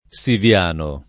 Siviano [ S iv L# no ]